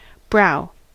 brow: Wikimedia Commons US English Pronunciations
En-us-brow.WAV